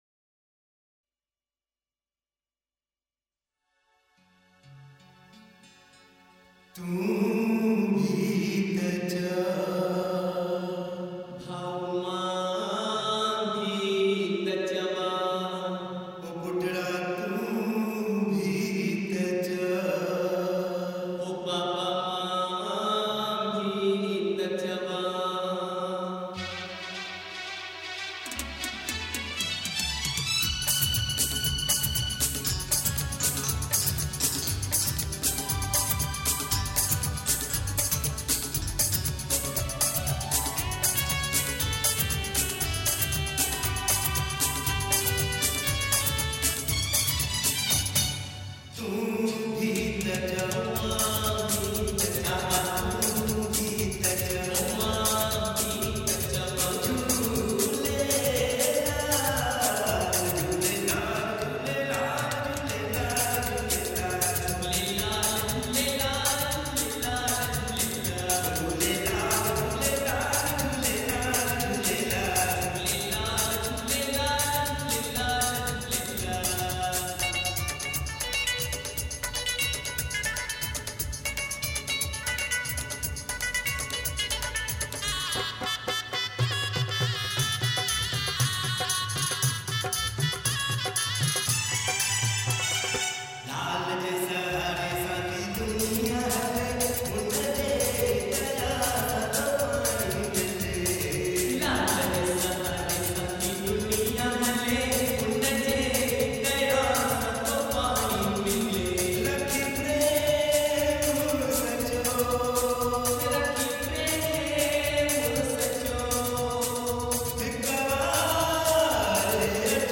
Sindhi Songs of Jhulelal